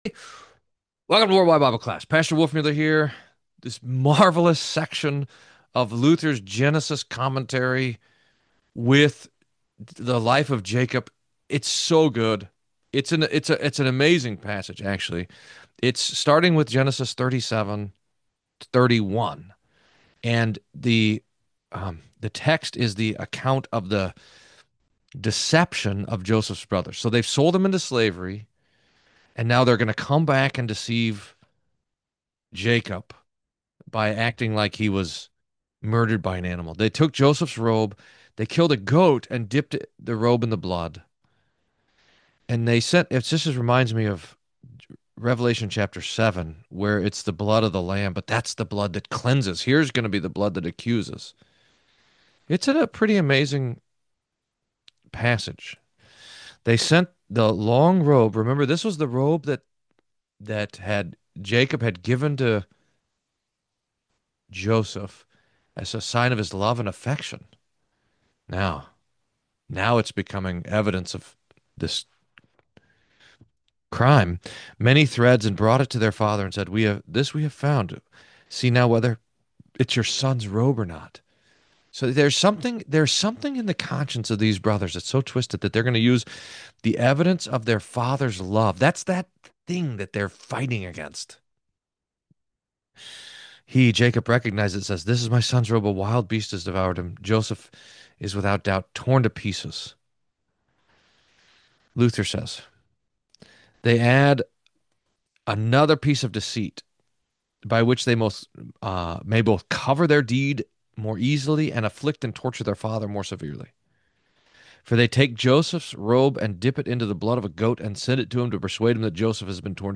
World-Wide Bible Class